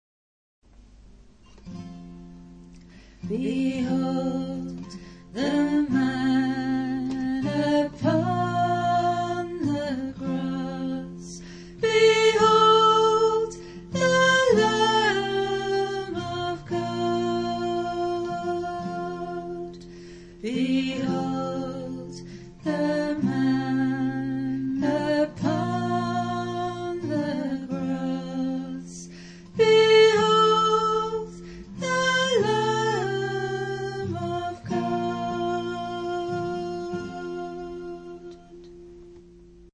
Shorter, more meditative, songs